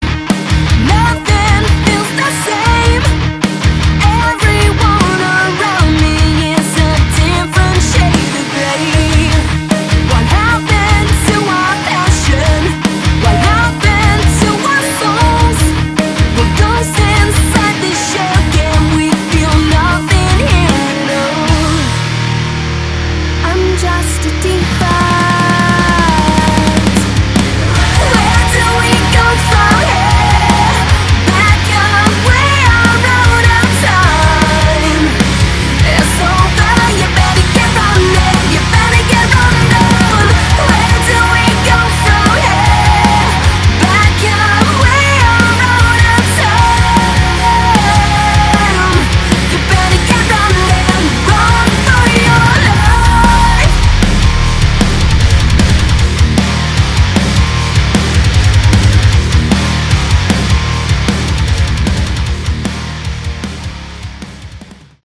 Category: Melodic Metal
lead vocals, guitars
bass, backing vocals
drums